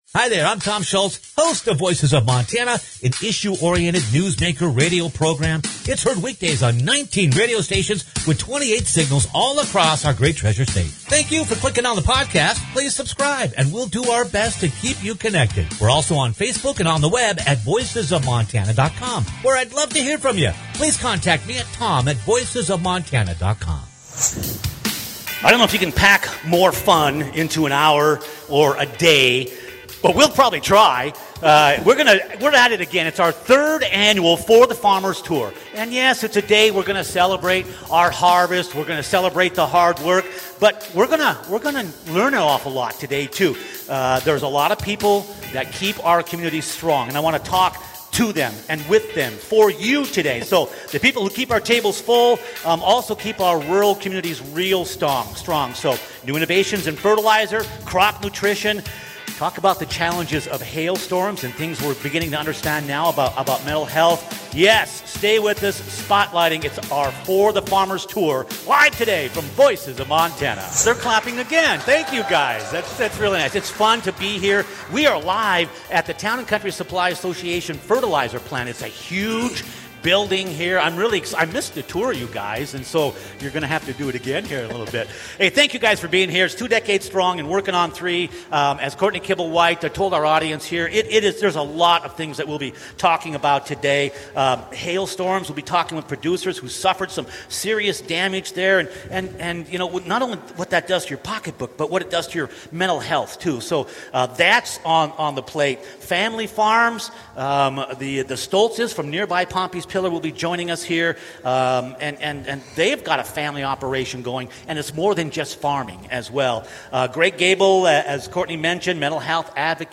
For the Farmers — LIVE - Voices of Montana
Click on the podcast to experience the Kick0ff broadcast of this 3rd annual For the Farmers appreciation tour.
for-the-farmers-live.mp3